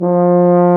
BRS TUBA F0M.wav